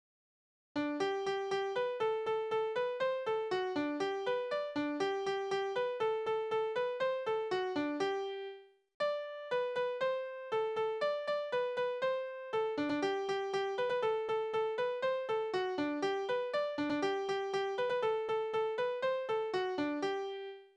Tanzverse:
Tonart: G-Dur
Taktart: 2/4
Tonumfang: Oktave
Besetzung: instrumental